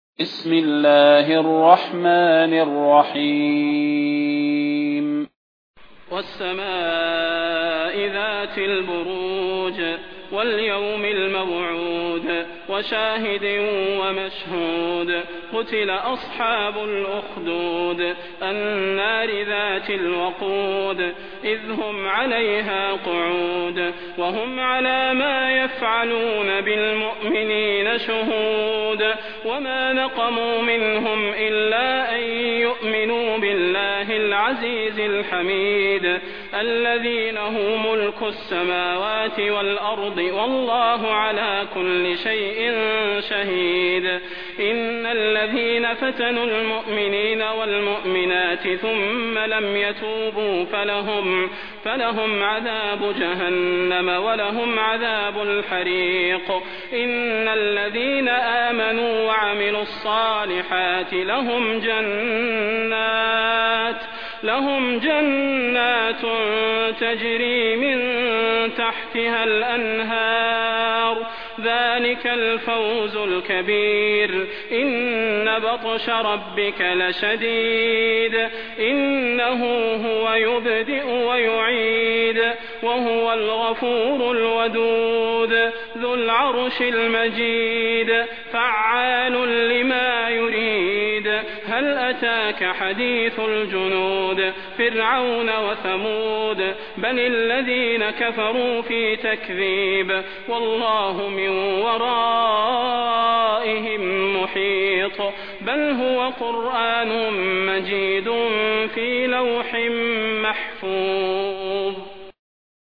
المكان: المسجد النبوي الشيخ: فضيلة الشيخ د. صلاح بن محمد البدير فضيلة الشيخ د. صلاح بن محمد البدير البروج